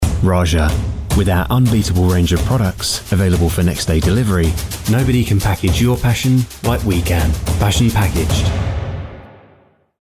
Youtube Ad – RAJA
BRITISH MALE VOICE-OVER ARTIST
Warm, neutral (non-regional) English accent
Raja-Voiceover.mp3